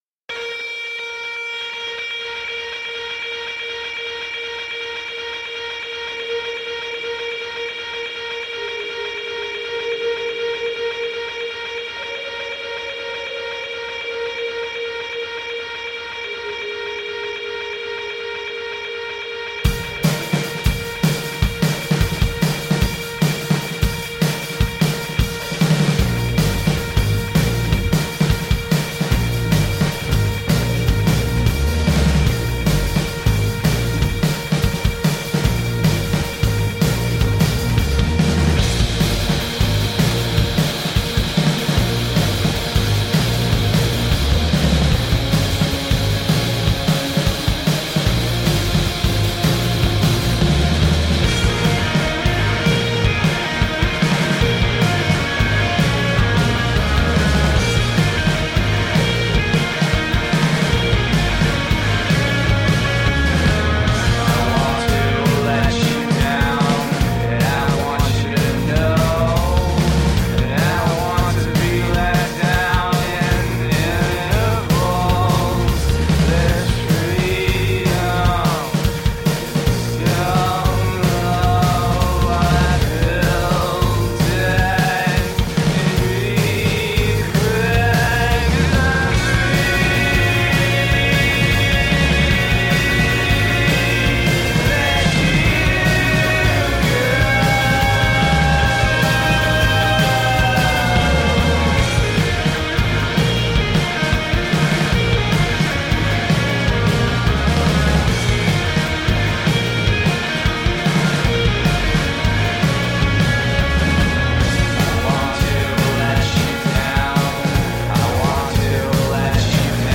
Melodic fury meets post-punk, shoegaze, alternative.
Tagged as: Alt Rock, Darkwave